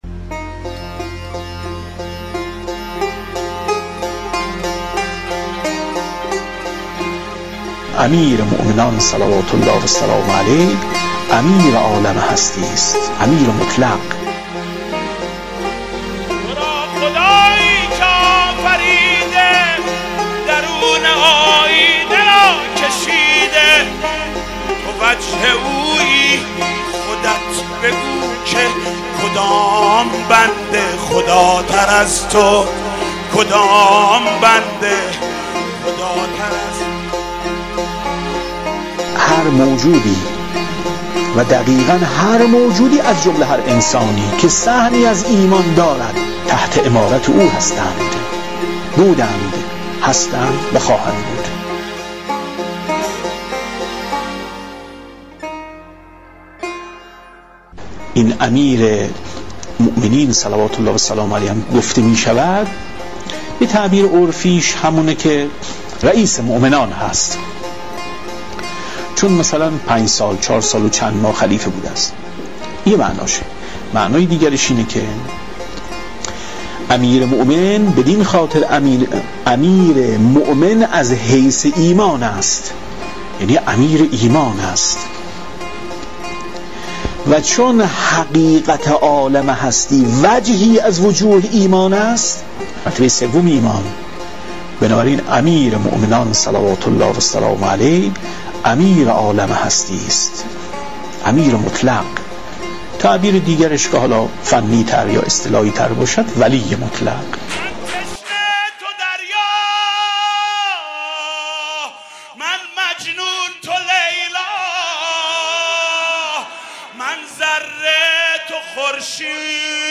دسته: آواهنگ